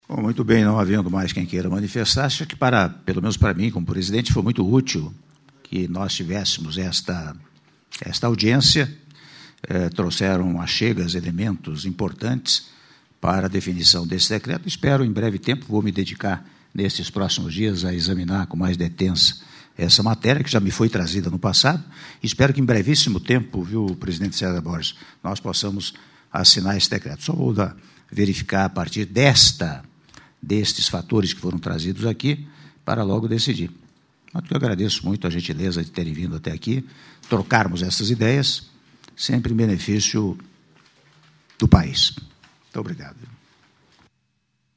Áudio do discurso do presidente da República, Michel Temer, no encerramento da reunião Decreto Relicitação- Brasília/DF- (44s)